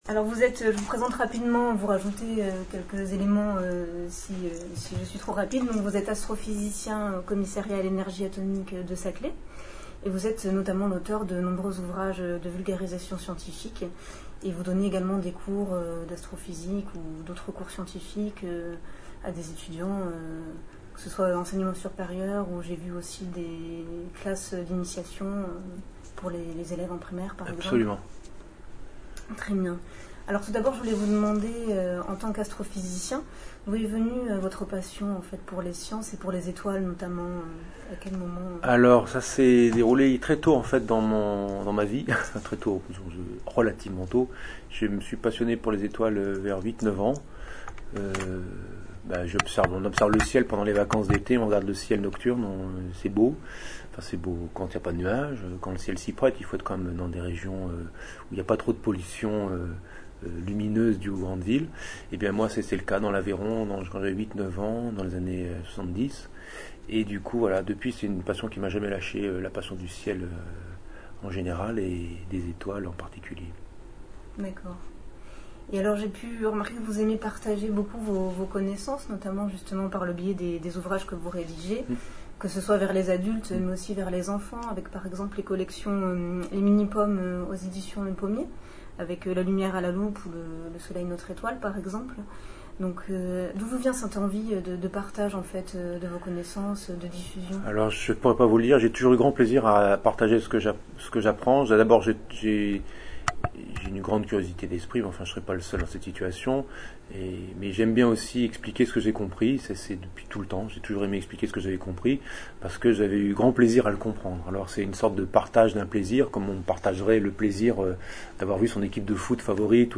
Interview Roland Lehoucq
RolandLehoucqUtopiales.mp3